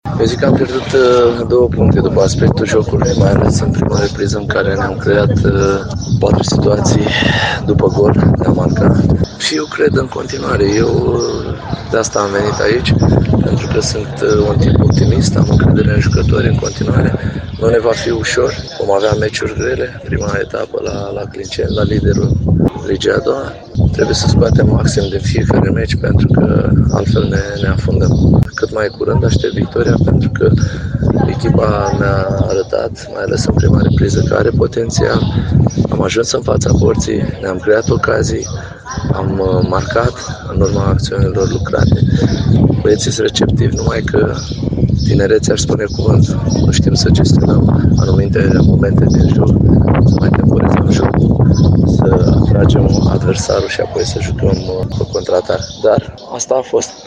Avem în continuare reacții din cele două tabere.